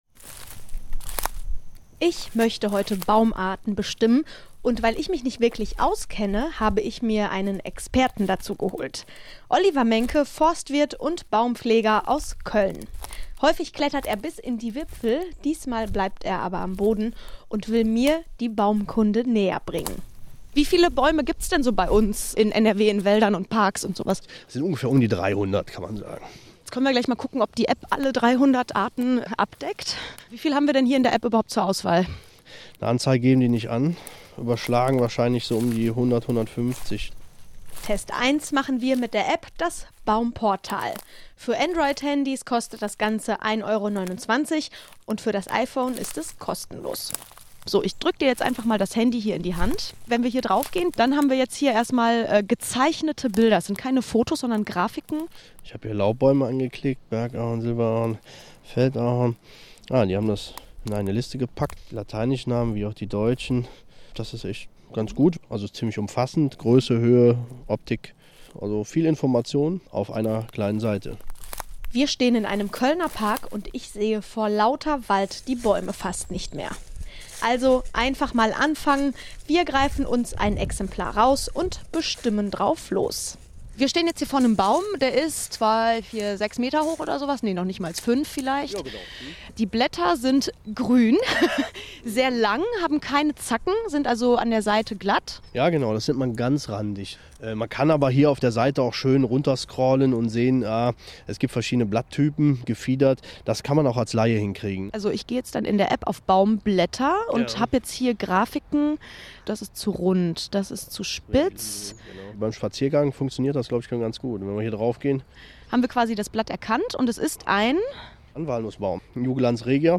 Wir haben mit dem WDR für Euch Baum Apps getestet. Radiobeitrag WDR Hörfunk 07. Oktober 2013